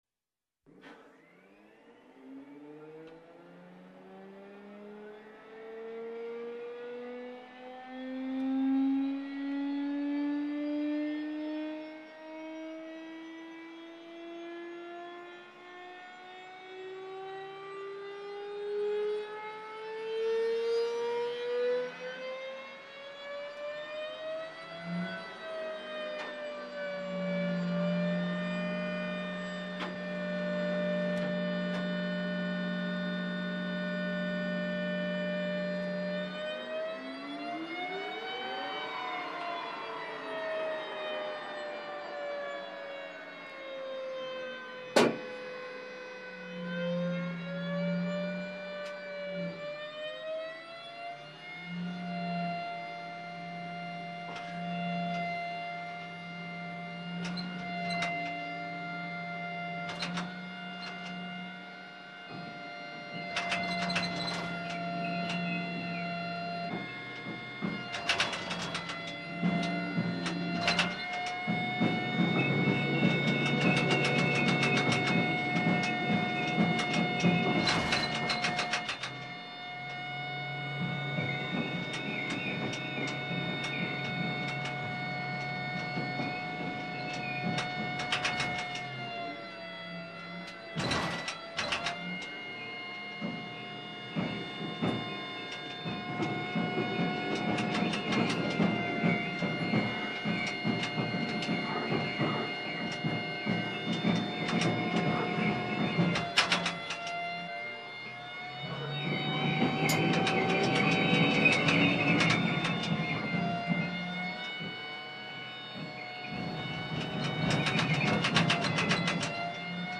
Звук танка — повороты башни
Танк V5-58 движение башни -1
Tank_V5-58_dvizhenie_bashni_2.mp3